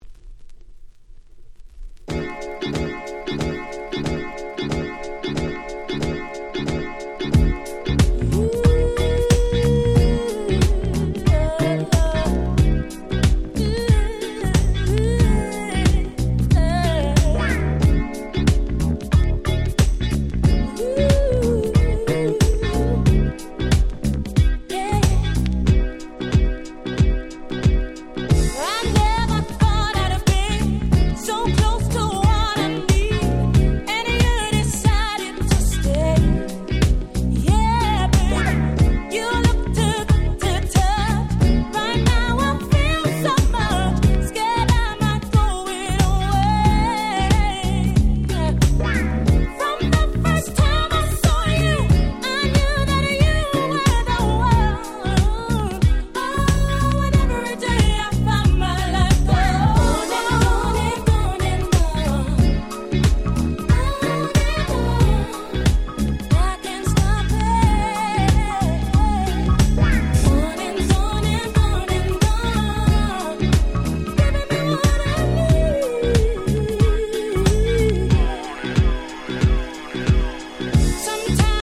96’ Super Nice UK Street Soul / R&B Compilation !!